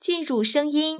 conf-entry_sound.wav